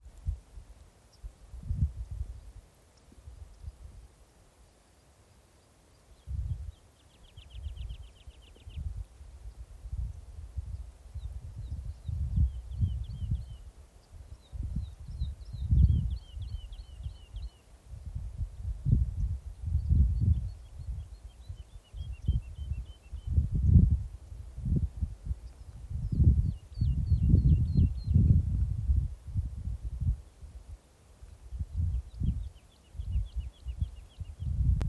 Putni -> Cīruļi ->
Sila cīrulis, Lullula arborea
StatussDzied ligzdošanai piemērotā biotopā (D)